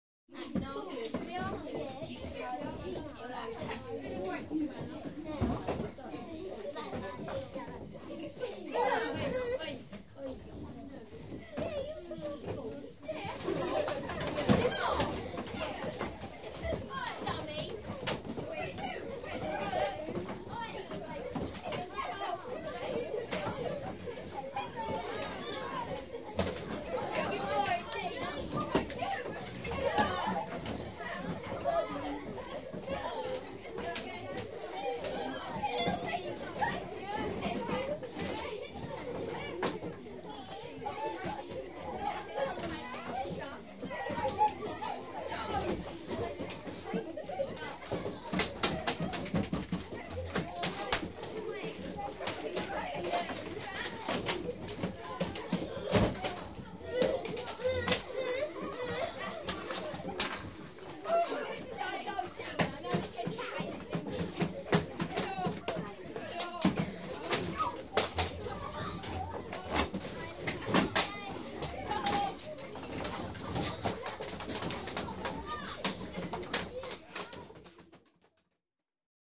Разговоры и шум небольшой группы детей в комнате